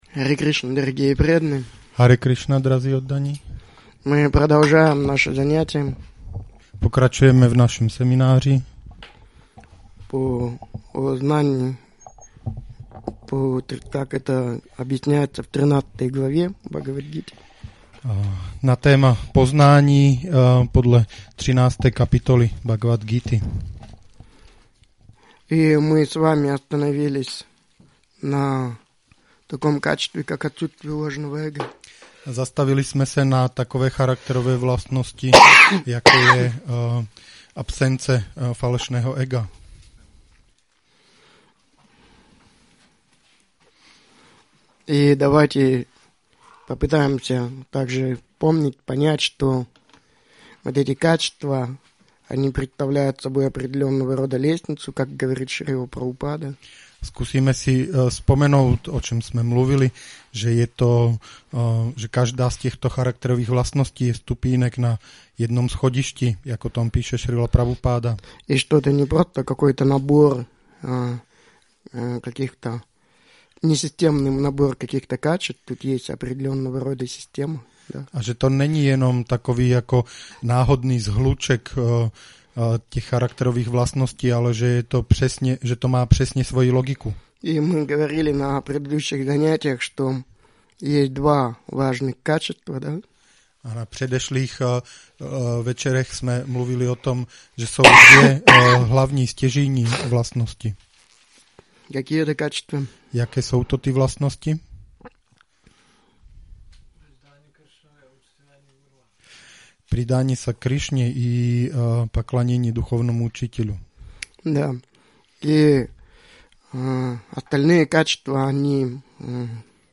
Seminář Jak vyvinout Vaišnavské vlastnosti 4